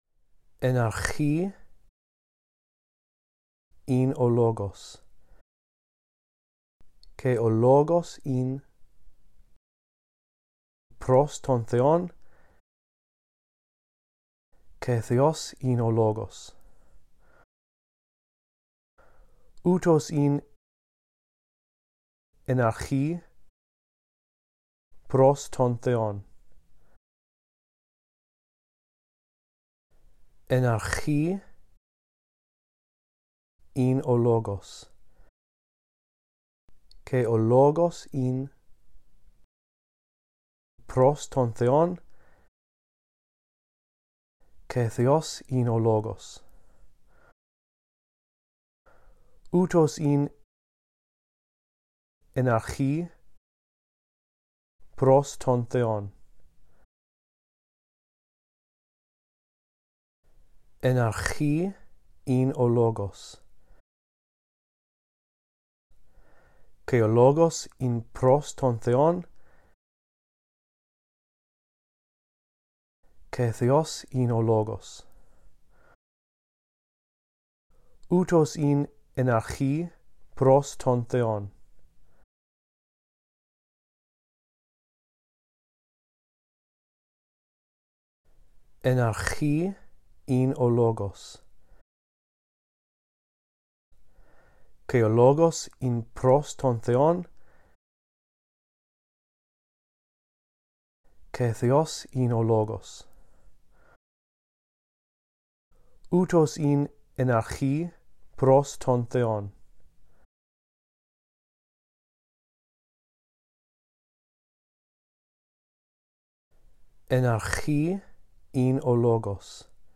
In this audio track, I read through verses 1-2 a phrase at a time, giving you time to repeat after me. After two run-throughs, the phrases that you are to repeat become longer.